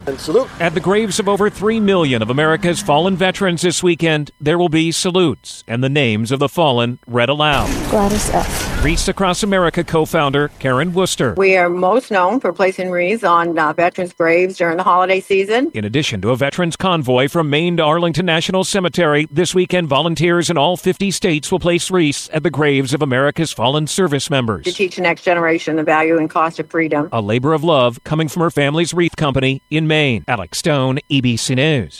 This weekend will bring a special moment at the gravesites of America’s fallen heroes. An organized effort for the holidays to place wreaths at millions of veteran gravesites.